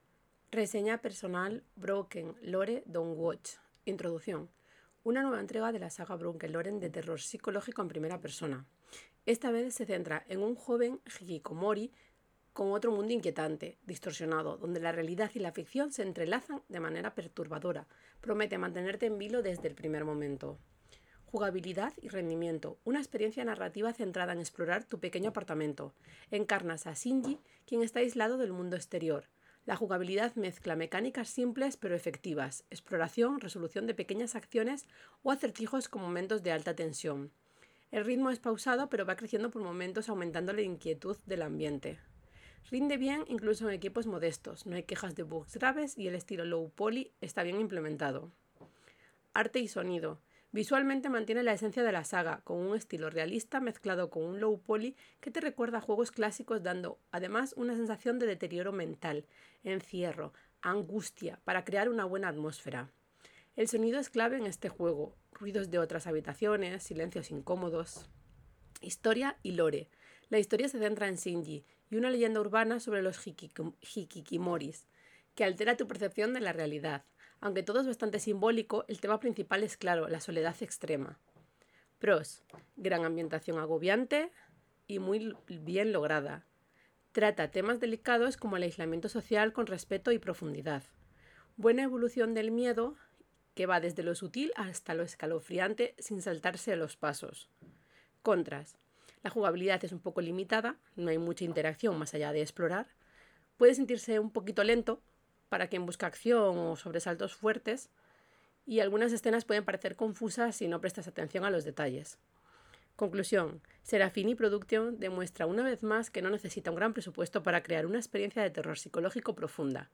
Reseña personal en audio: